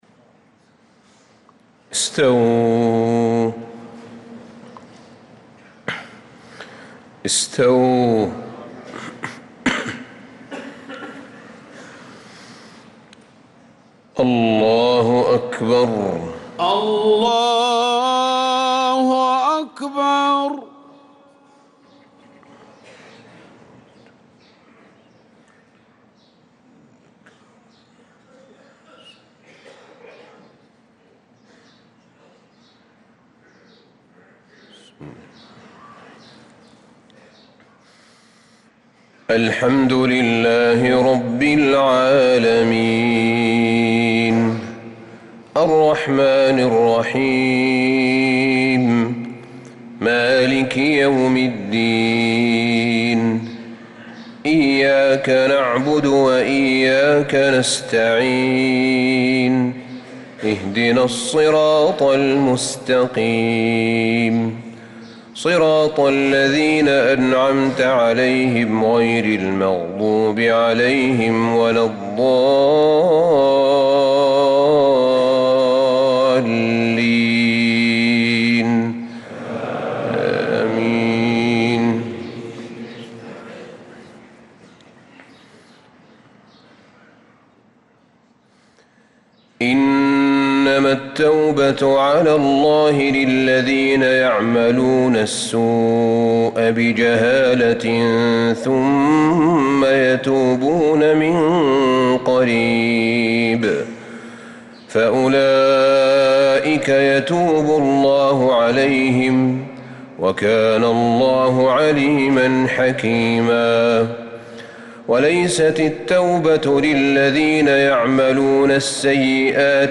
صلاة الفجر للقارئ أحمد بن طالب حميد 19 ذو الحجة 1445 هـ
تِلَاوَات الْحَرَمَيْن .